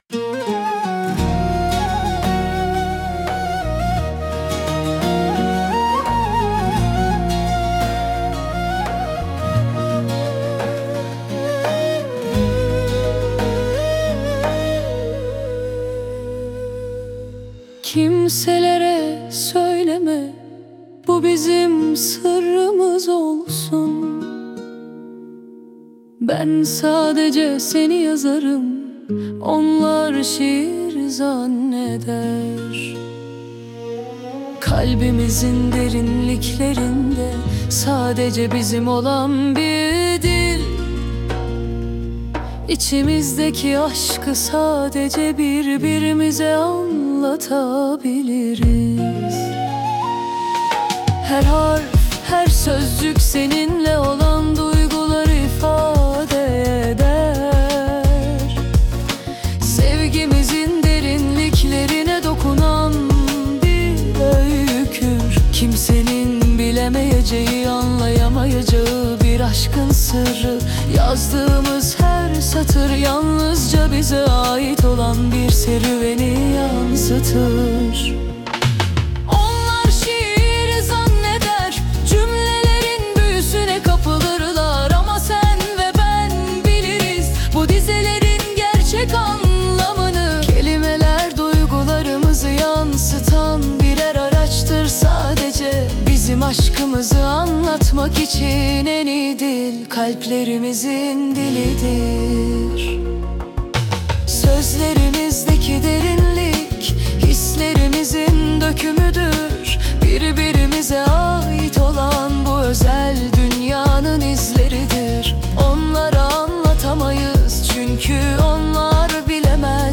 🎤 Vokalli 18.10.2025